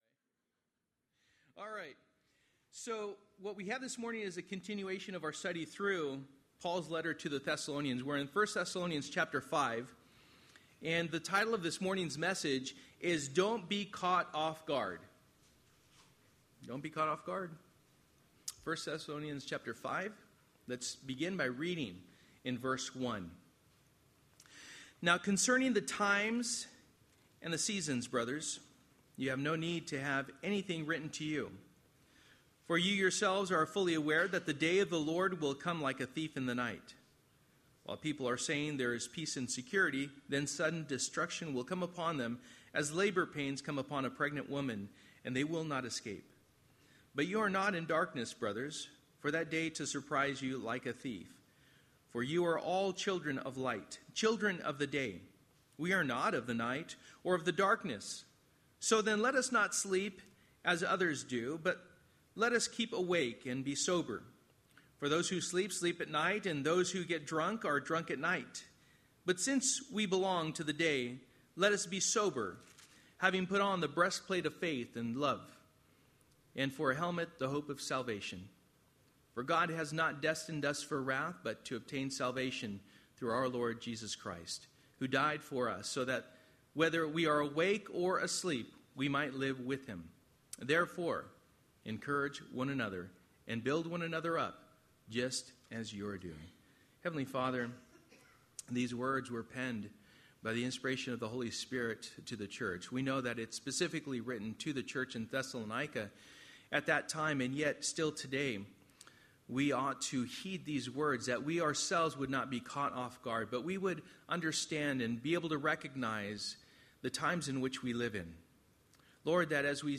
Passage: 1 Thessalonians 5:1-11 Service: Sunday Evening